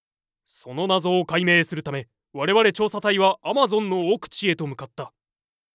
パロディ系ボイス素材　2